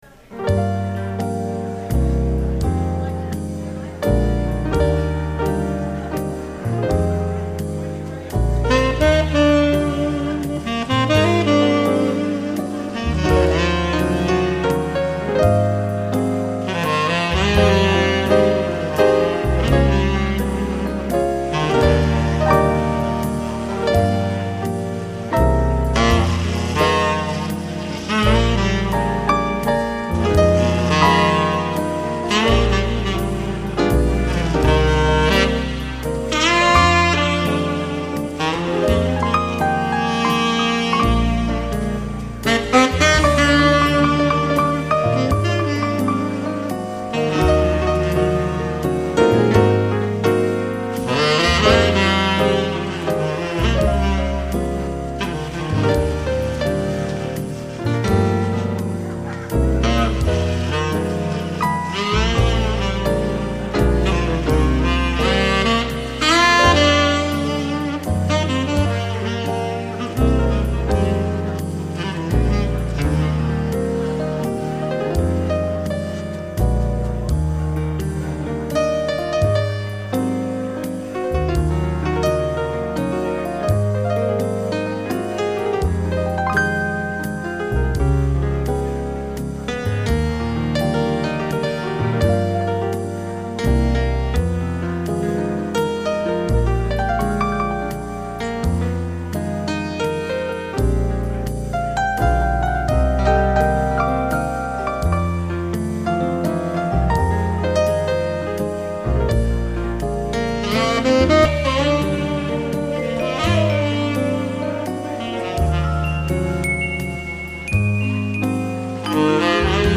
keyboard
saxophones
trombone